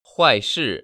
[huàishì] 화이스  ▶